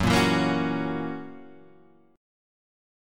F# 7th